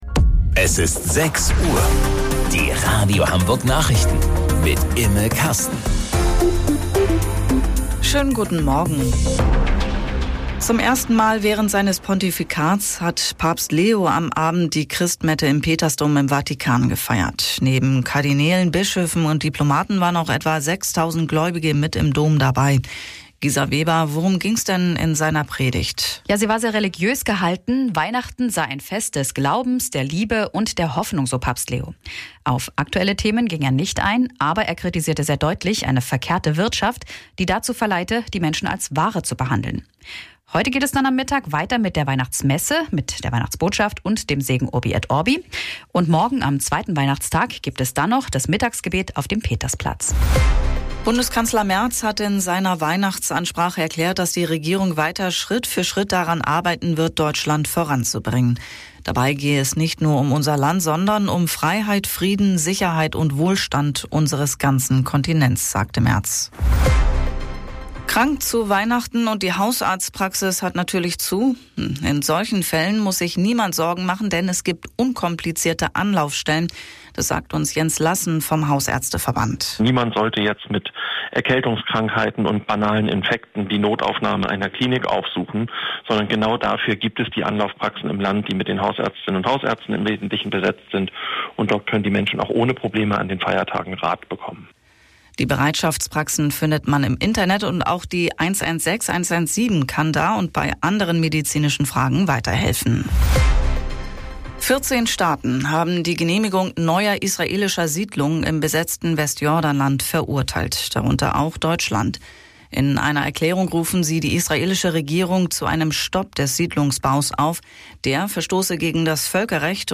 Radio Hamburg Nachrichten vom 25.12.2025 um 06 Uhr